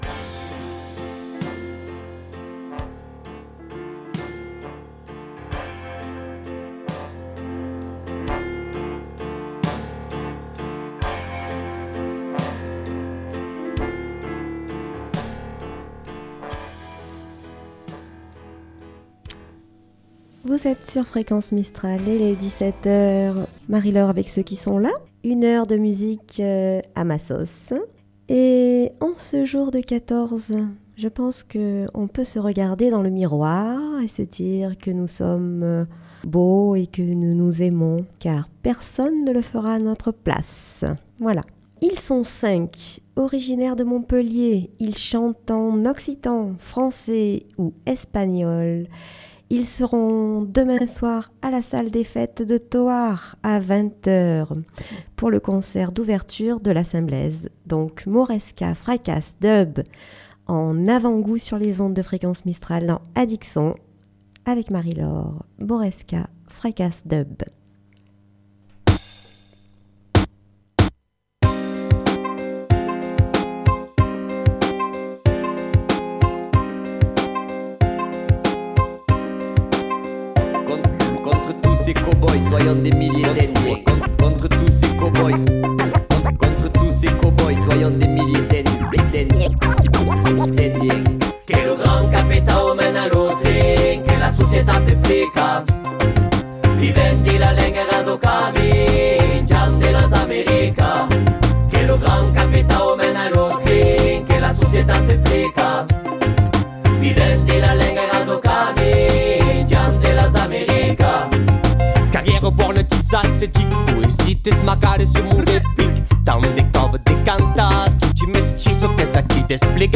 Addic Son - Emission musicale du 14 février
chanson française mais aussi, du blues, du rock, du reggae, du rap, jazz, etc.